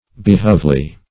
behovely - definition of behovely - synonyms, pronunciation, spelling from Free Dictionary Search Result for " behovely" : The Collaborative International Dictionary of English v.0.48: Behovely \Be*hove"ly\, a. & adv. Useful, or usefully.